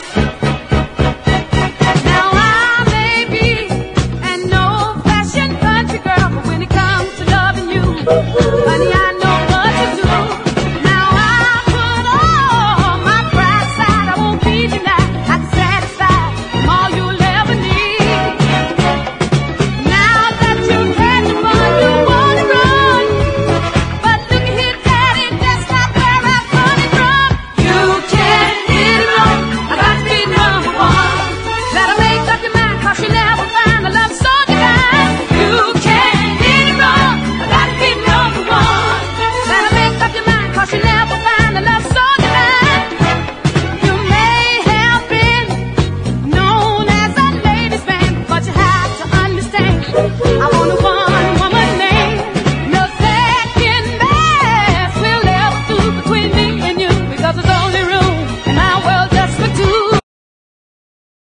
SOUL / SOUL / 70'S～ / DISCO / PARADISE GARAGE (US)
とことんキレよく弾んだグルーヴがステップを誘います！